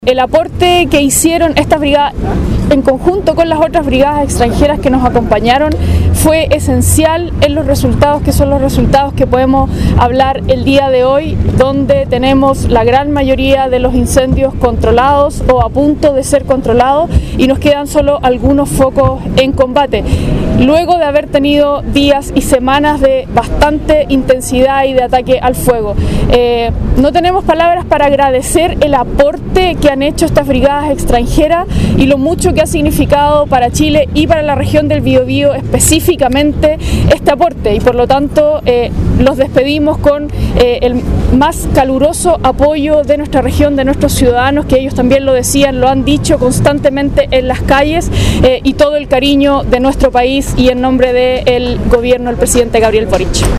La Plaza Independencia, en el centro de Concepción, fue el escenario de la ceremonia en la que autoridades regionales despidieron a los brigadistas mexicanos y españoles que, durante cuatro semanas, participaron del combate de los incendios forestales en la zona centro sur.